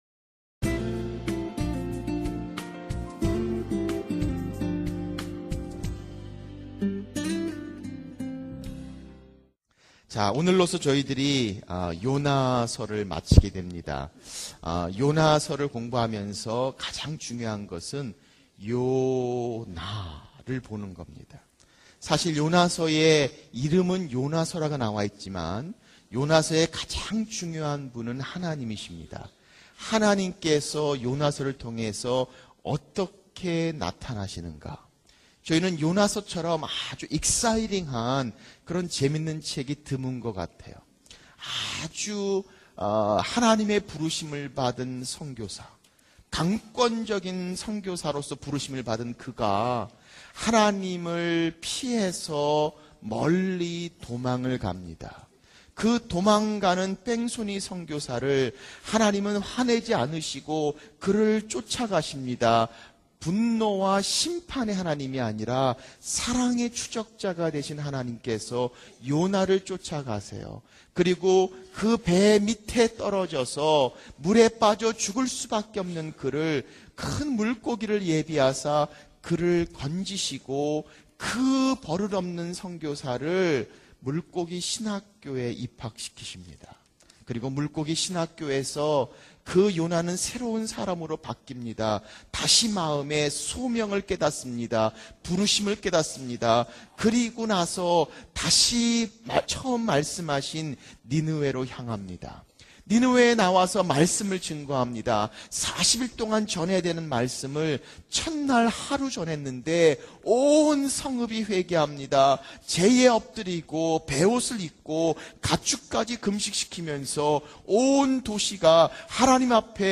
설교 | (4) PD 하나님